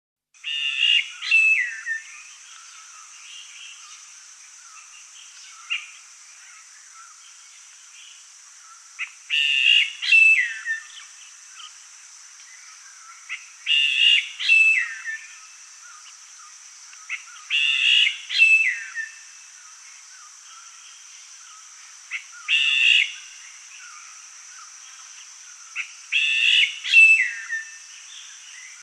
Black Bulbul – a song
Black-Bulbul.mp3